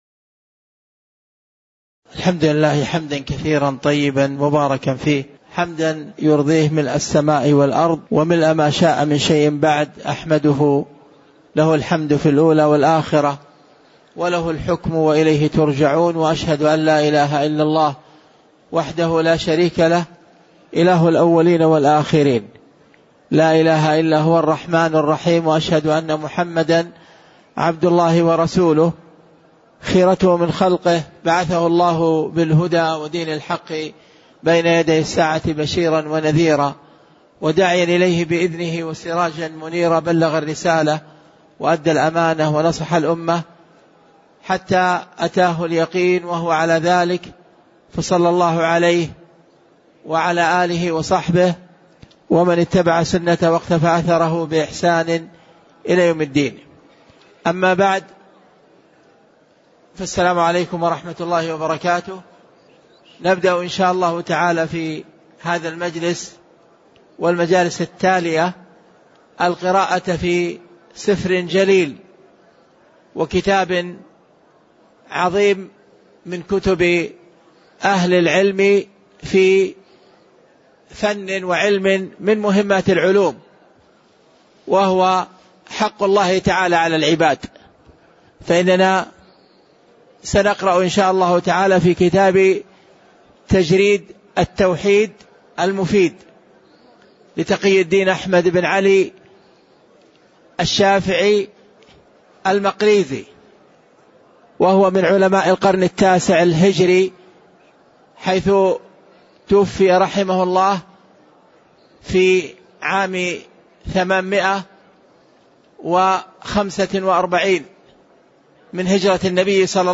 تاريخ النشر ٢٥ ربيع الأول ١٤٣٩ هـ المكان: المسجد النبوي الشيخ